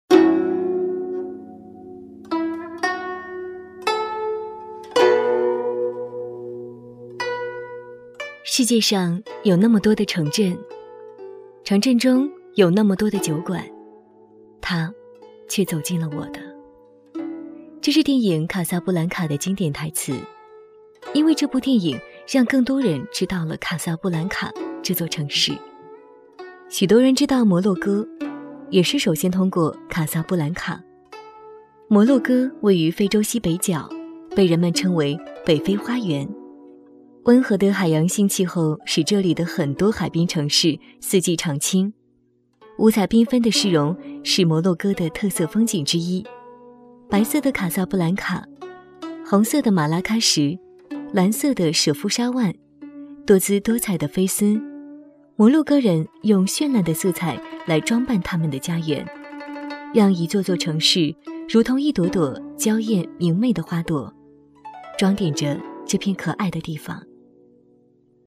百字18元（女声列表）
擅长：专题片、宣传片、旁白、飞碟说MG、角色、朗诵等等.
说明：低价≠低质，以下老师均为中国传媒学院播音专业毕业，超高的性价比，高端设备，专业录音棚。